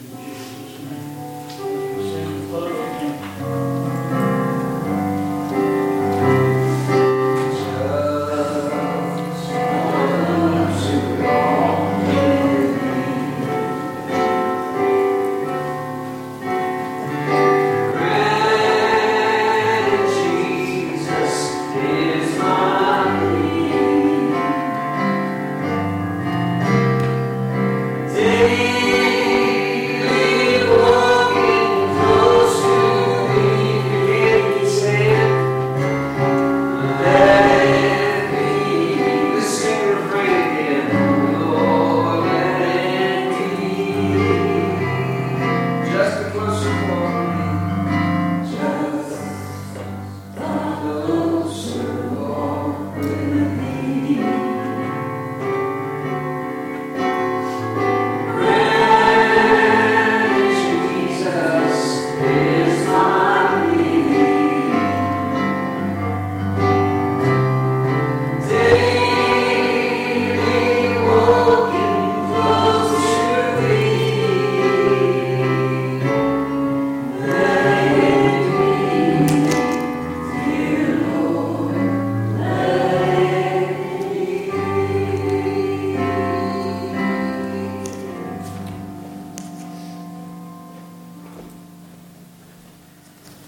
Hymn: "Just a Closer Walk with Thee"